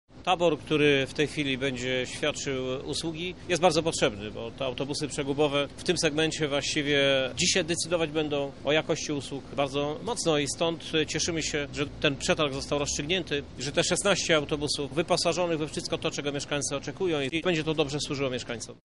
– mówi Krzysztof Żuk, prezydent miasta.